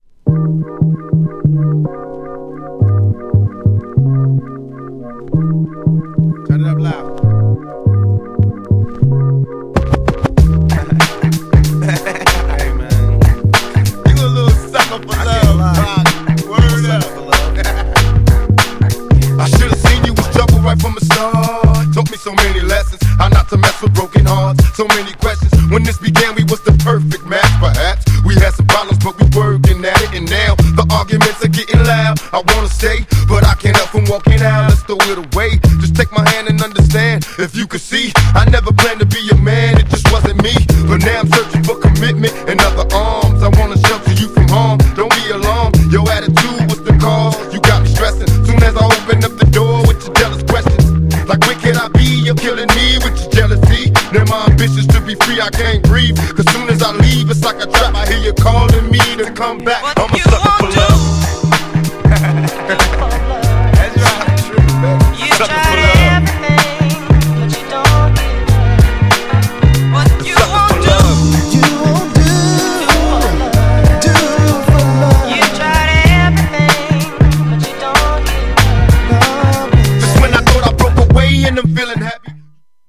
FUNKY＆メロウなUK REMIXと
GENRE Hip Hop
BPM 91〜95BPM
SMOOTHなフロウ
ウェッサイ入ってマス
フックでR&Bなコーラスがイイ!